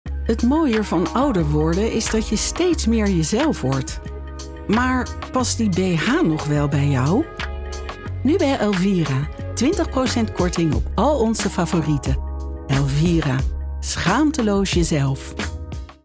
Travieso, Versátil, Seguro, Amable, Cálida
Telefonía